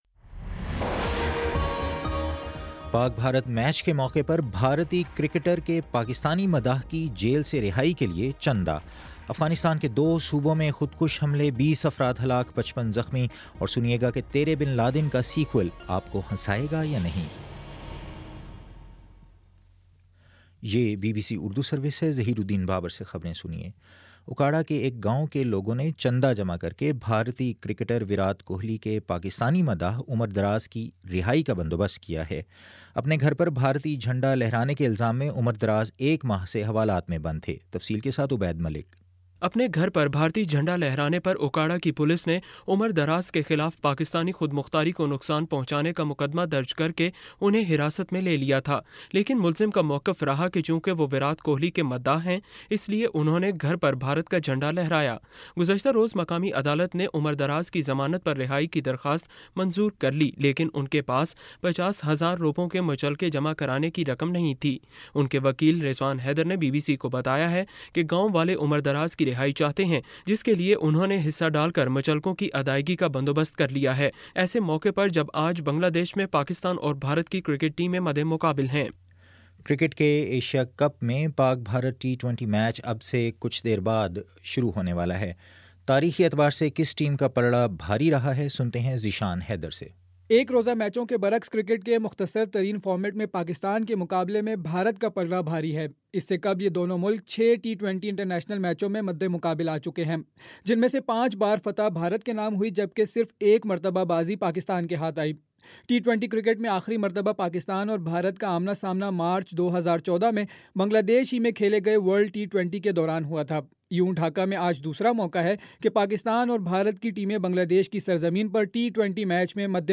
فروری 27 : شام چھ بجے کا نیوز بُلیٹن